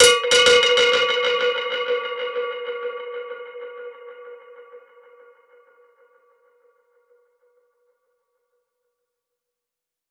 DPFX_PercHit_C_95-01.wav